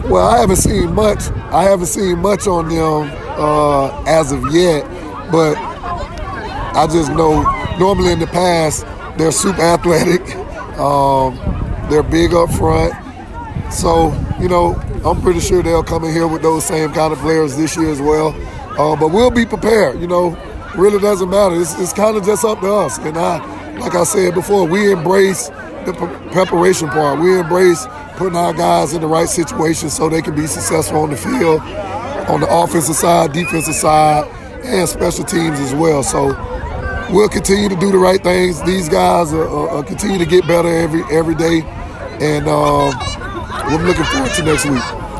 live reaction post-game